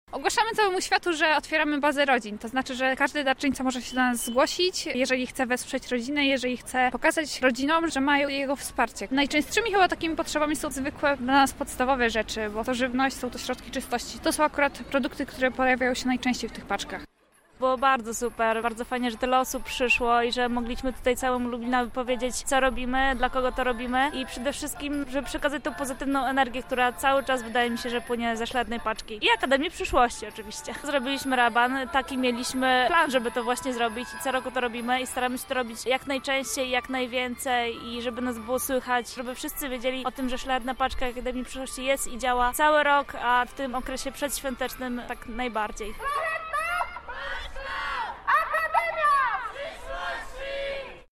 W weekend wymaszerowali spod Zamku w Lublinie, aby dotrzeć na Plac Litewski i urządzić tam wspólne zabawy i taniec. Na miejscu był nasz reporter: